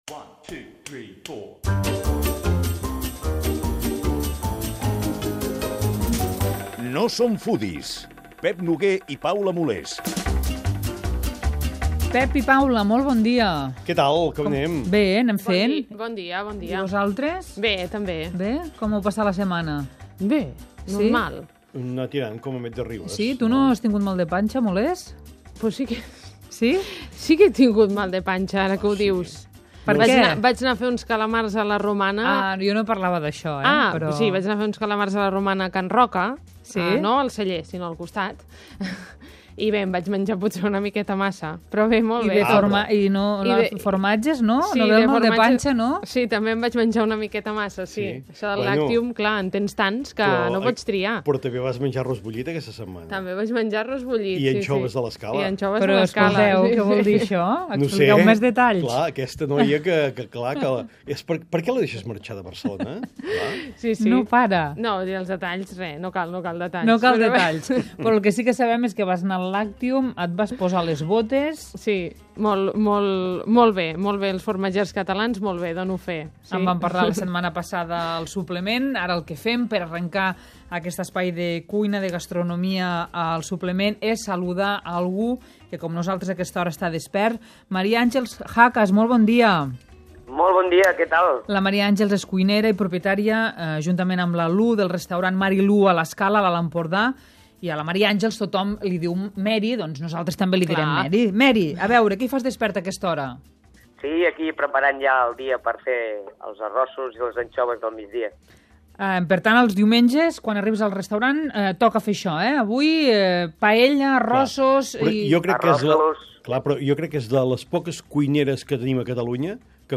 Parlem amb una refugiada marroquina i amb un professor de Migracions i Alimentació per veure com viatgen pel món les receptes i els…